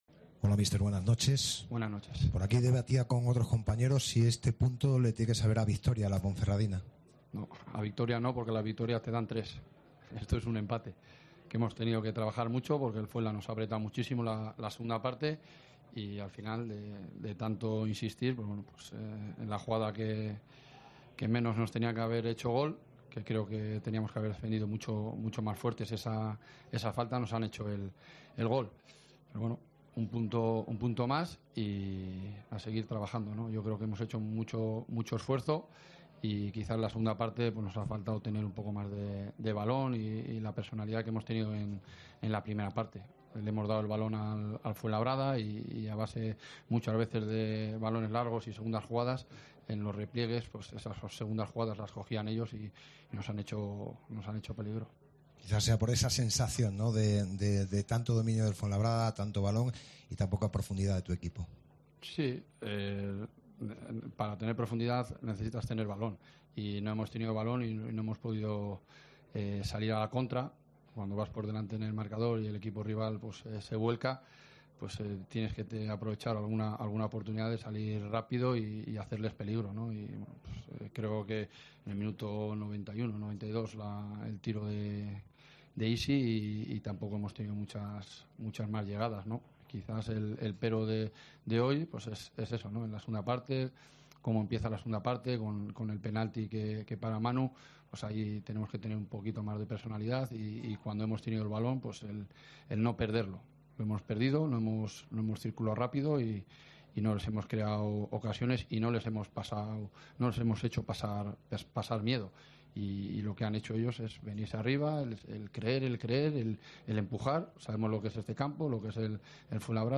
Escucha aquí las palabras del míster de la Deportiva Ponferradina, Jon Pérez Bolo, tras el empate 1-1 en el campo del Fuenlabrada